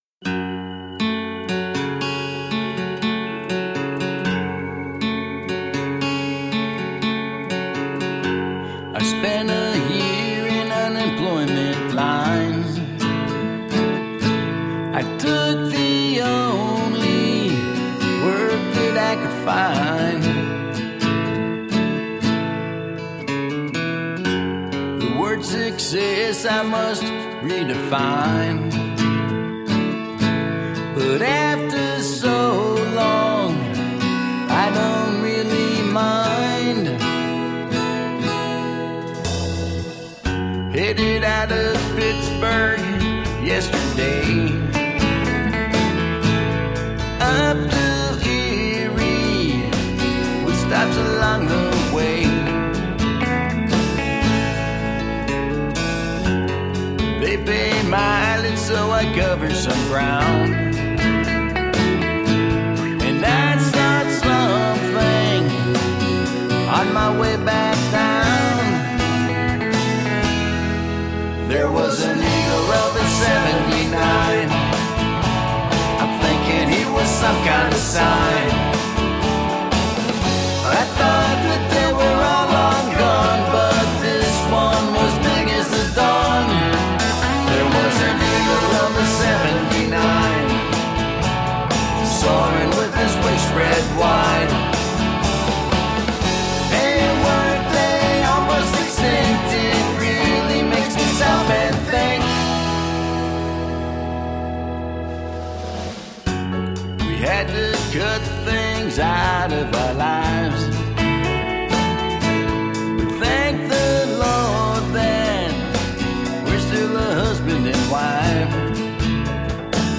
Roots rock meets contemporary folk.
Tagged as: Alt Rock, Folk